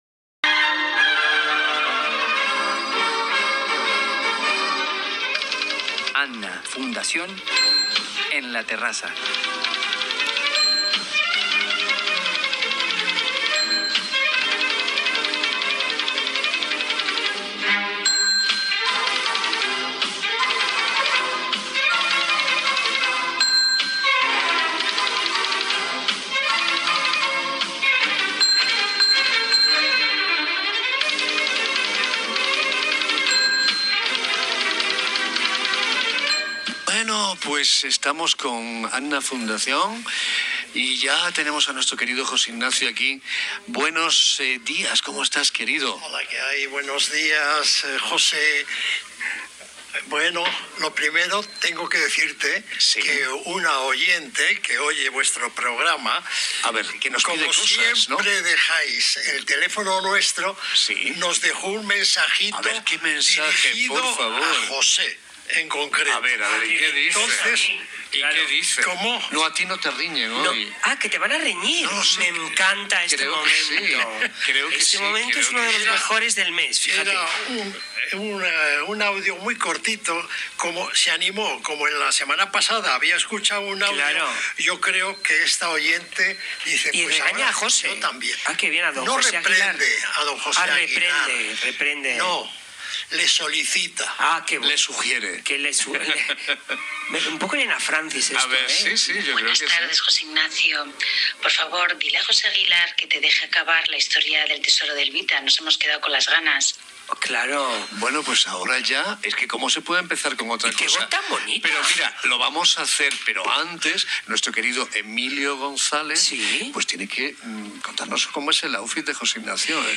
AN-A FUNDACIÓN EN RADIO INTERCONTINENTAL MADRID 95.4 FM hoy jueves 20.11.2025